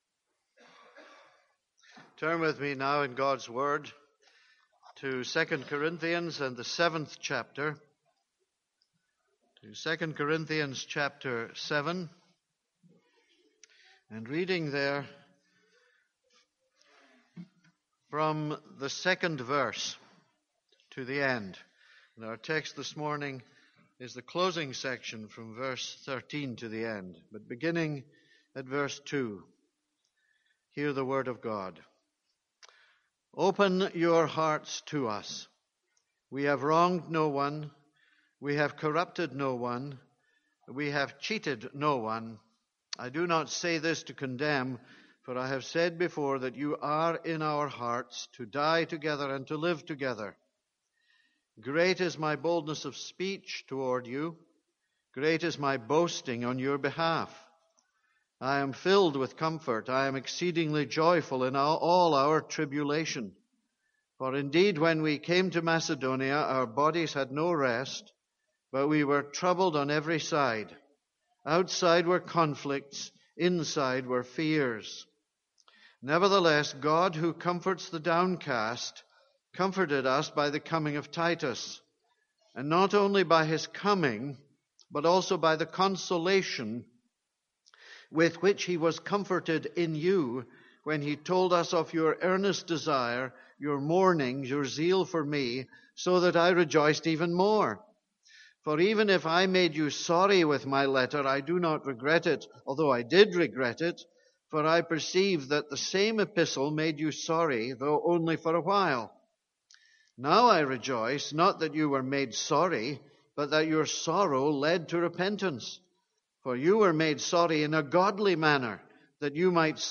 This is a sermon on 2 Corinthians 7:13-16.